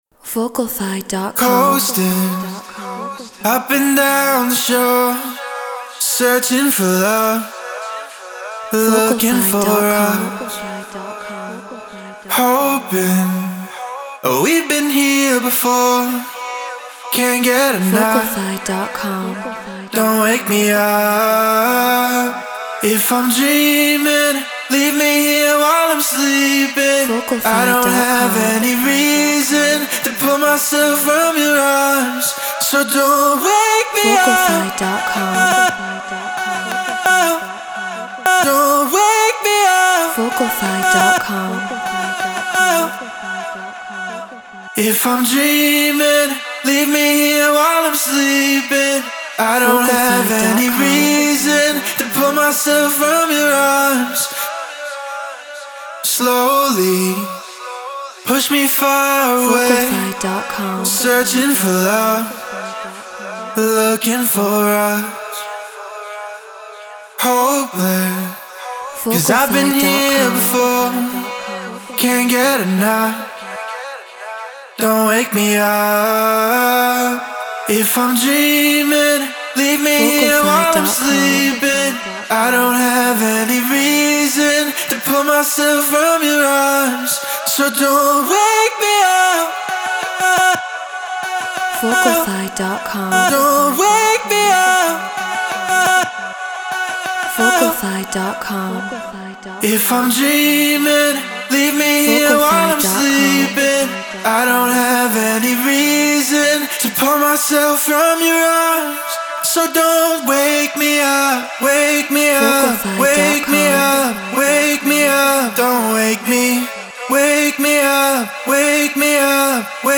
Drum & Bass 174 BPM F#min
RØDE NT1 Focusrite Scarlett Solo FL Studio Treated Room